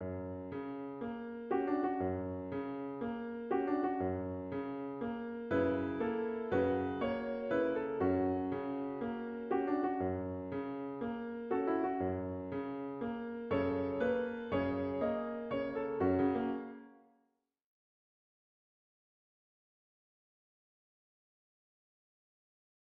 Ce thème, qui n’est pas sans évoquer George Gershwin, est bien entendu directement inspiré du blues, le la naturel de la main droite étant à considérer comme une note bleue, la main gauche jouant un la dièse, sur un accord de fa dièse majeur.